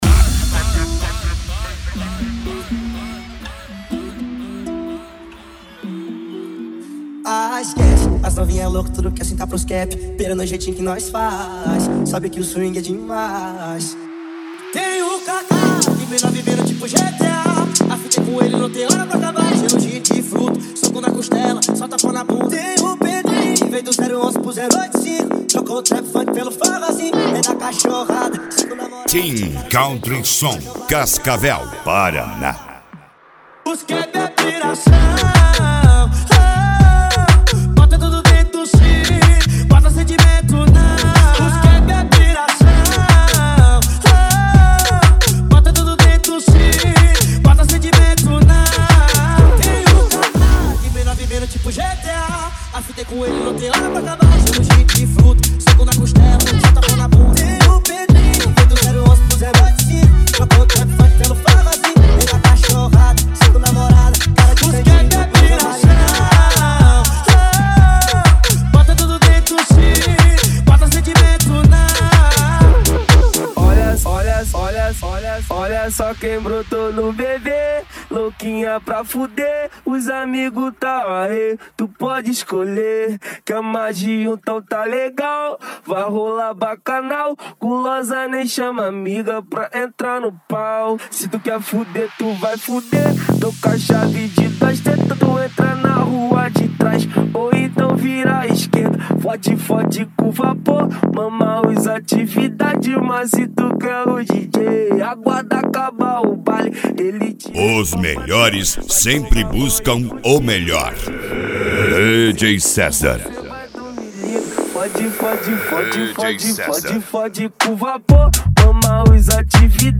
Funk
Funk Nejo
Mega Funk